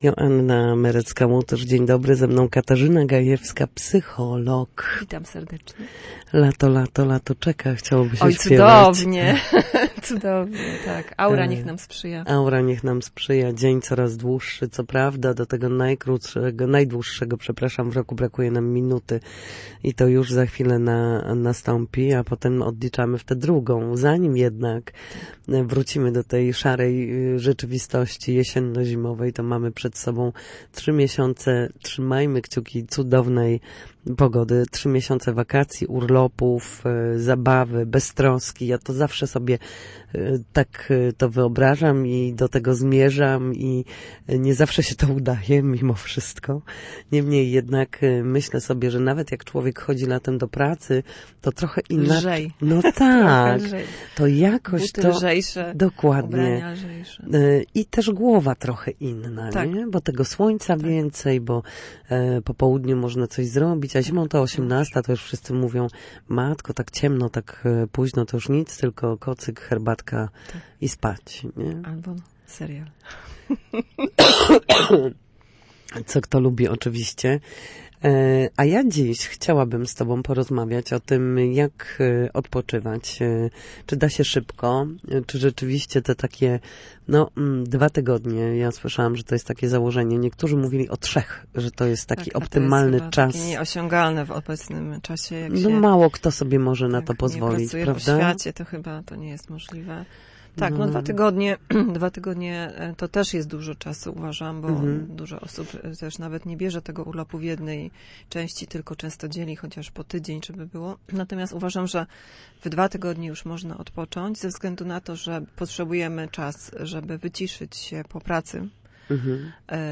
W każdą środę po południu w Studiu Słupsk Radia Gdańsk dyskutujemy o tym, jak wrócić do formy po chorobach i